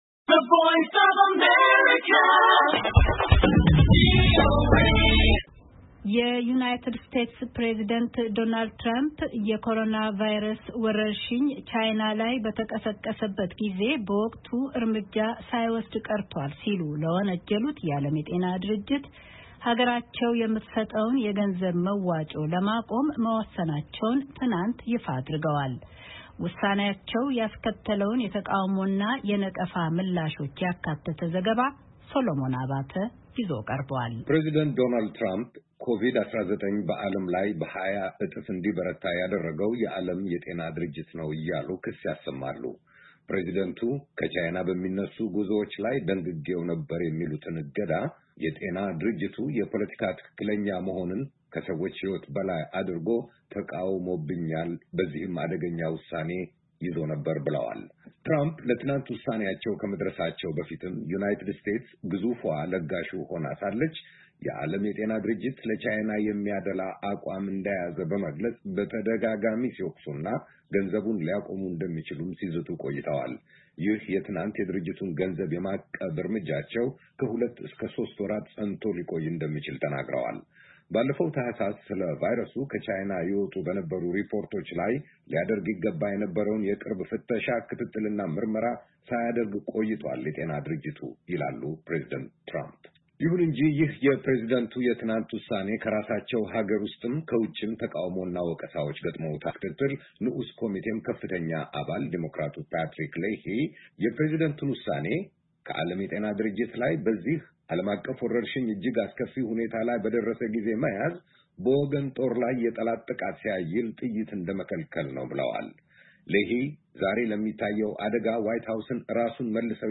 የዩናይትድ ስቴትስ ፕሬዚዳንት ዶናልድ ትረምፕ የኮሮናቫይረስ ወረርሽኝ ቻይና ላይ እንደተቀሰቀሰ በጊዜው ዕርምጃ ሳይወሰድ ቀርቷል ሲሉ ለወነጀሉት የዓለም የጤና ድርጅት ሀገራቸው የምትሰጠውን ገንዘብ ለማቆም መወሰነቸውን ትናንት ይፋ አድርገዋል። ውሳኔያቸው ያስከተለውን ተቃውሞና ነቀፋ ምላሹን ያካተተ ዘገባ ይዘናል።